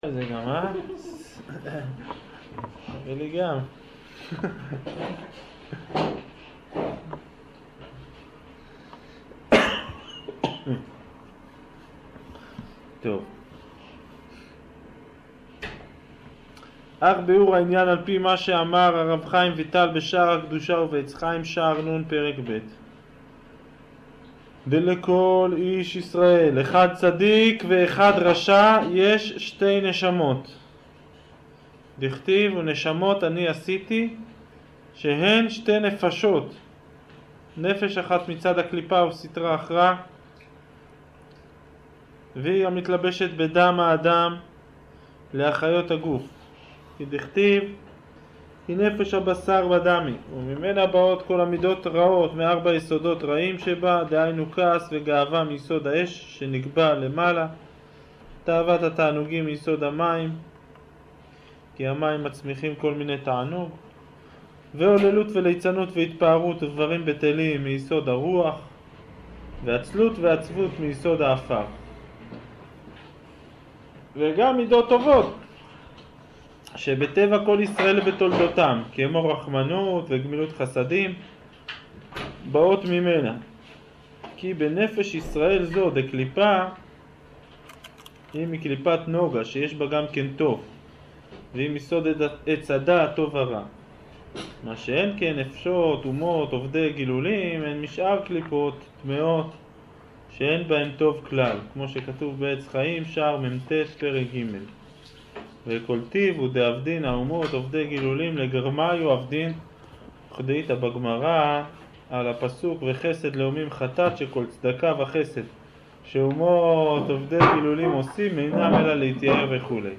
שיעור סוף פרק א'